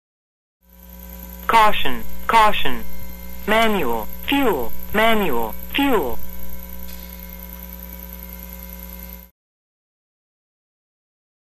Voice Correction Computer
Betty Alone; Computerized Warning Message In Cockpit. Close Perspective. Jet.